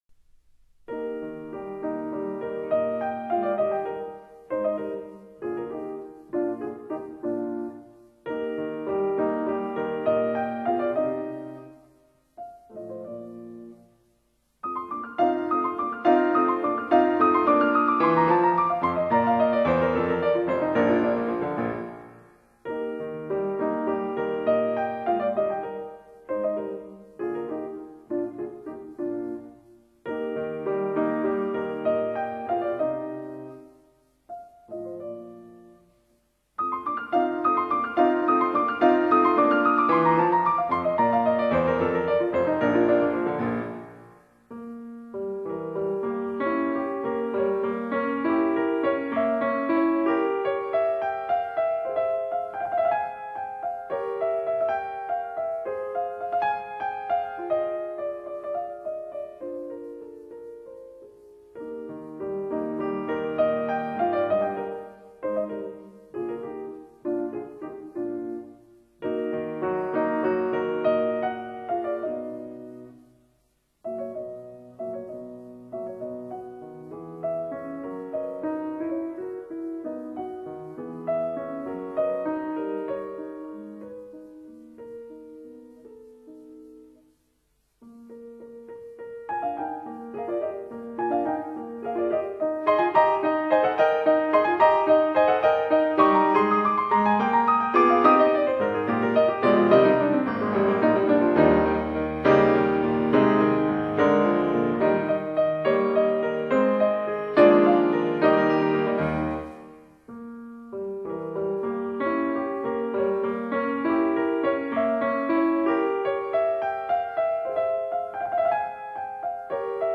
Klaviersonate No. 4